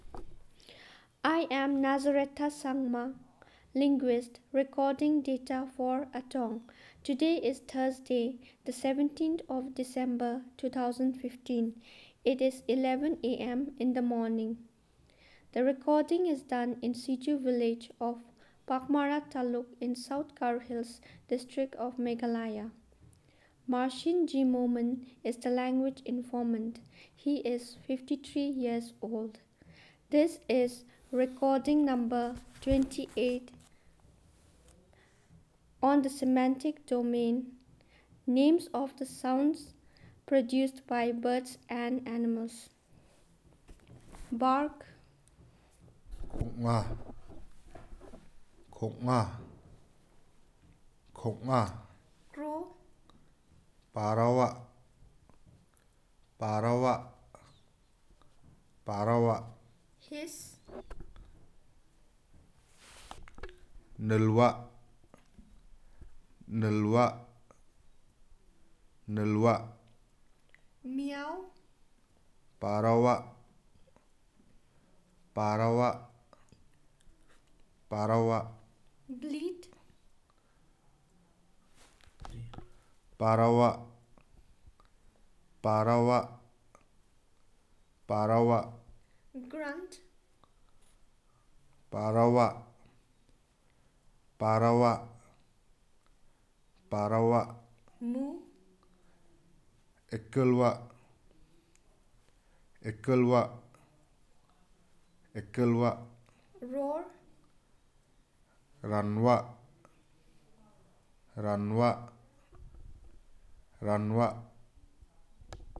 Elicitation of words about sounds produced by animals and birds